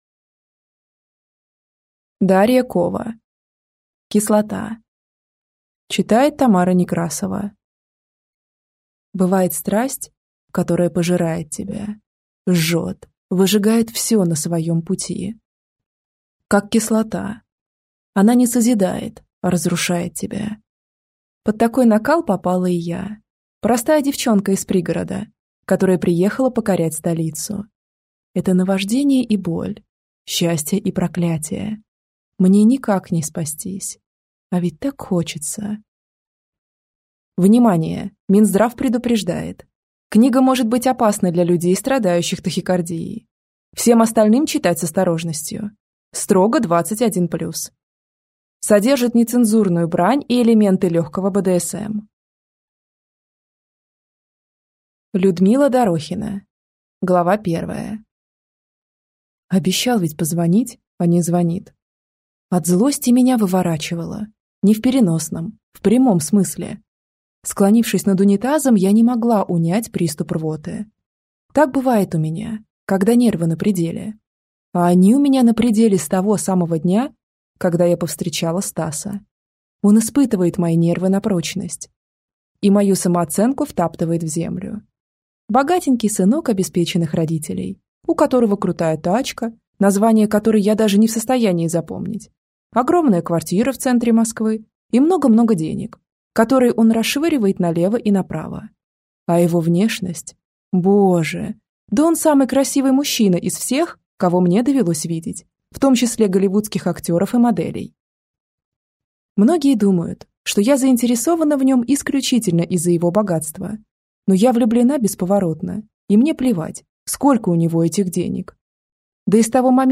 Аудиокнига Кислота | Библиотека аудиокниг